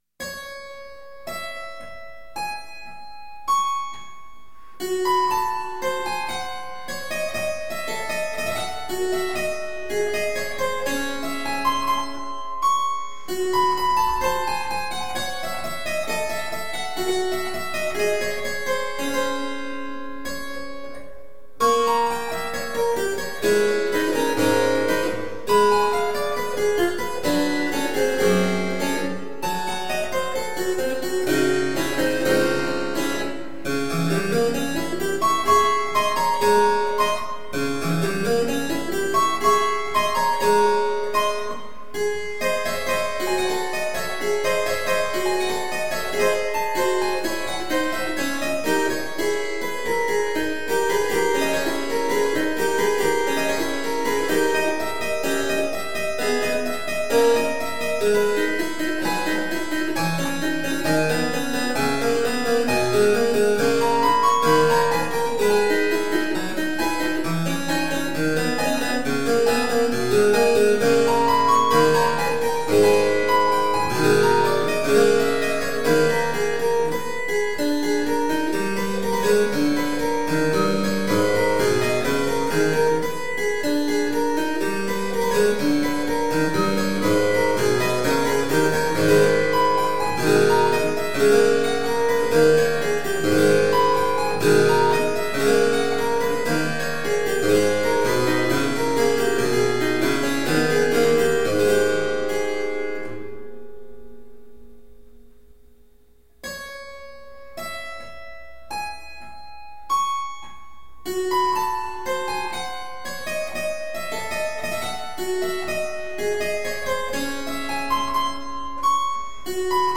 Solo harpsichord music.